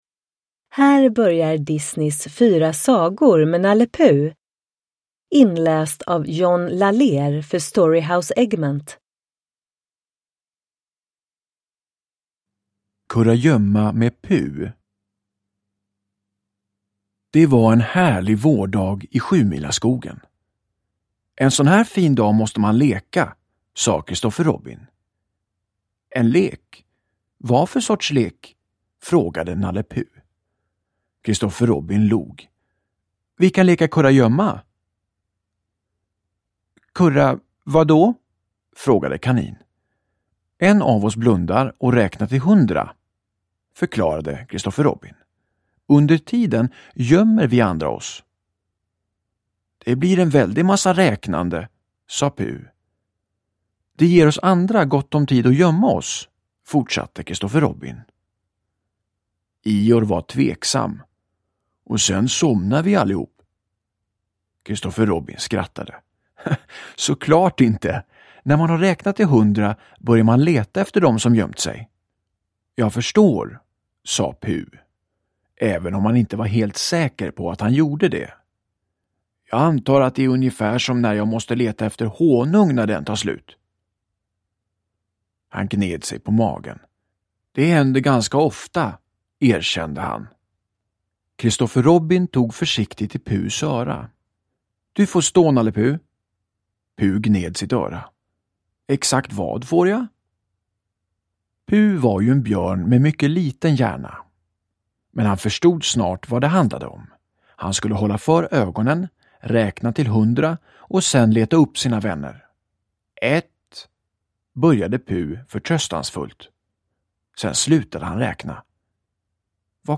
Fyra sagor med Nalle Puh – Ljudbok – Laddas ner